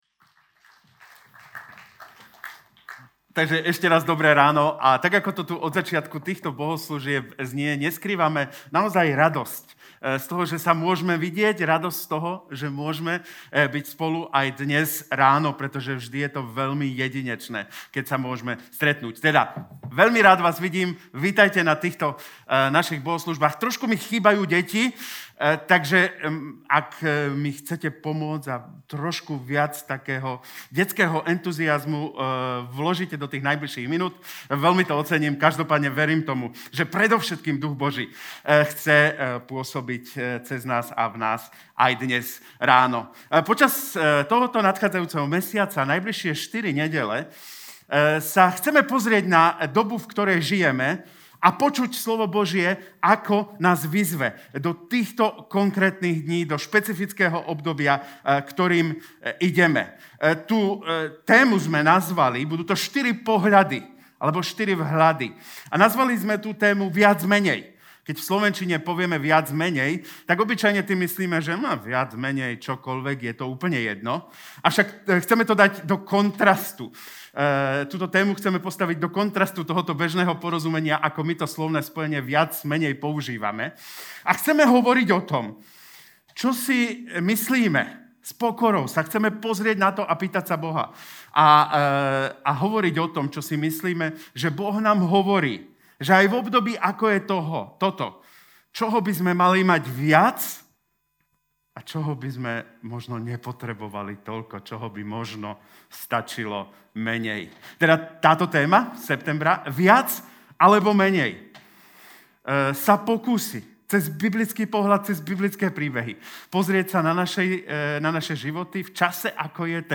Cez jeden z príbehov Ježišovho uzdravenia slepého si počas prvej kázne tejto série všimneme, kam nás dostáva otázka: „prečo“ a čím by sme ju mohli nahradiť. Podobne ako Ježišovi učeníci aj my v ťažkých obdobiach podliehame zvodu hľadania vinníka namiesto toho, aby sme sa stali katalyzátorom Božieho jedinečného pôsobenia v živote nášho blížneho.